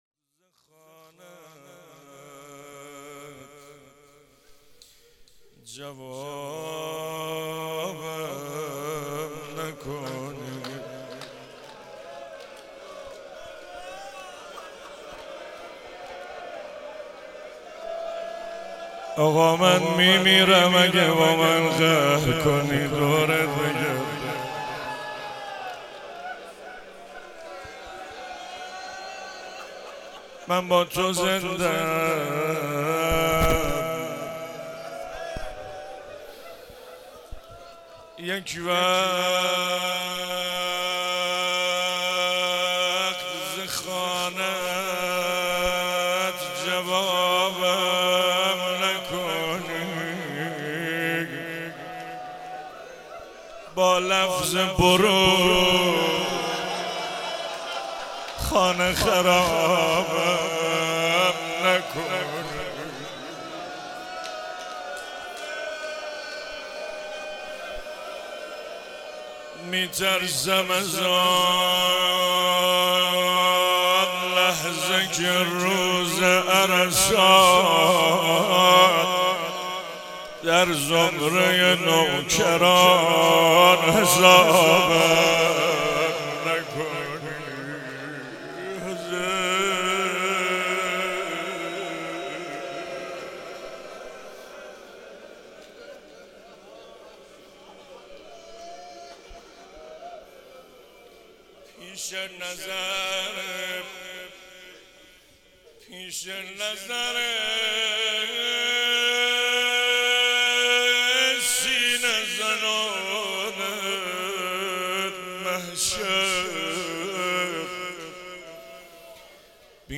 مراسم هفتگی 22 تیر 96
حسینیه حضرت زینب (سلام الله علیها)
روضه سید الشهدا(ع)